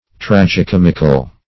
Tragi-comic \Trag`i-com"ic\, Tragi-comical \Trag`i-com"ic*al\,